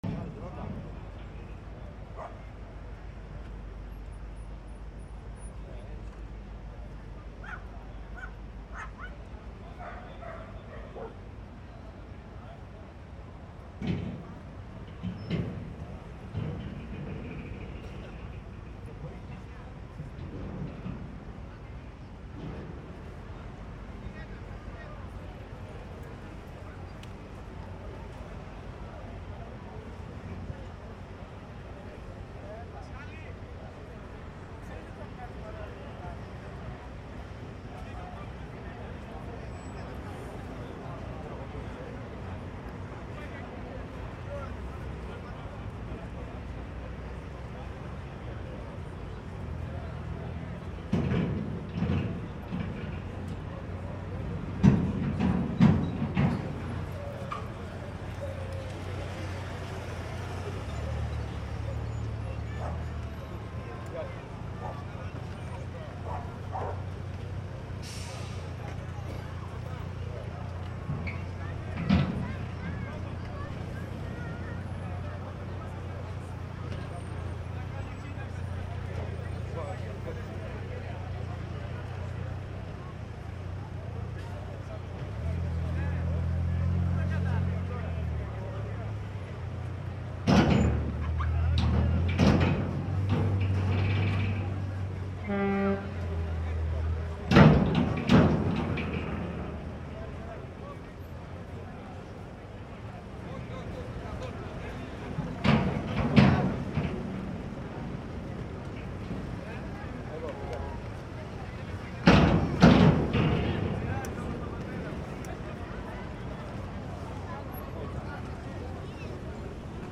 This is the sound of a ferry for the island of Chios loading at the port of Piraeus in the spring of 2016. I recorded it standing on the deck looking out at the densely packed tents of refugees and asylum seekers in empty warehouses on the harbour side.
Part of the Migration Sounds project, the world’s first collection of the sounds of human migration.